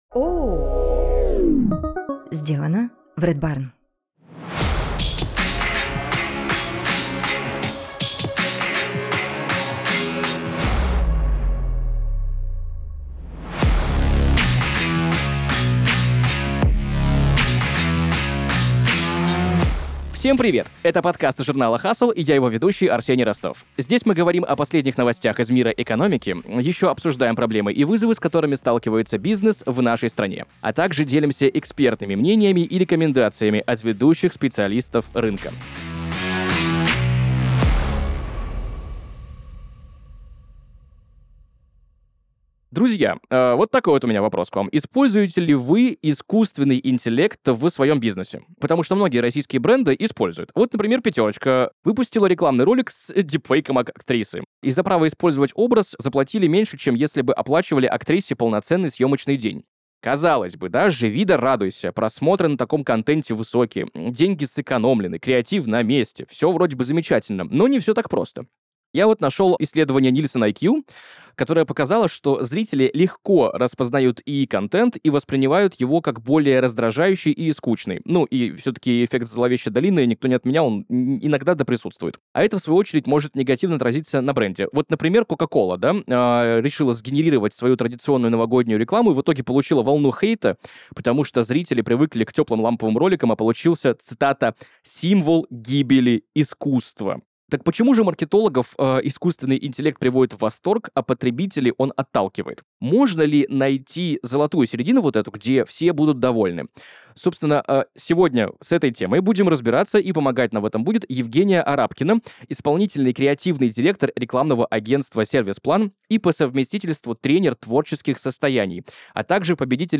В каждом выпуске ведущий с гостями говорит о новостях из мира бизнеса, управления и финансов на территории России.